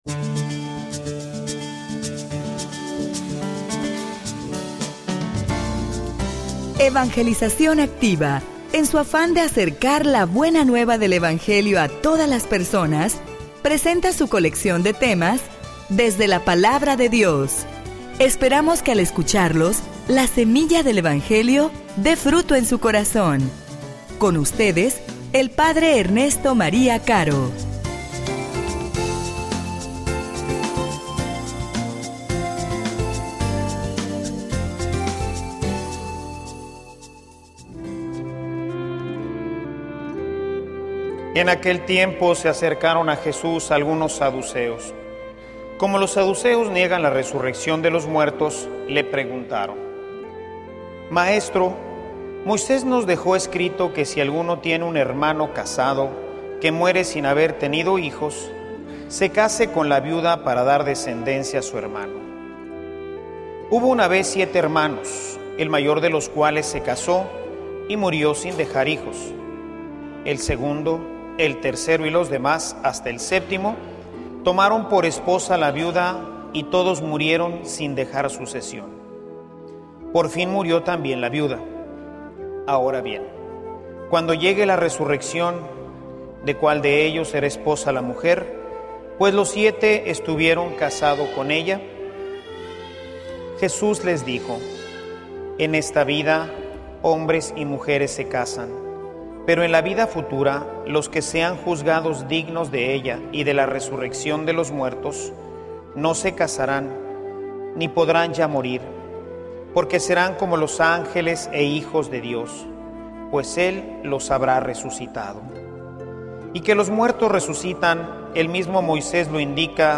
homilia_No_comprometas_tu_futuro.mp3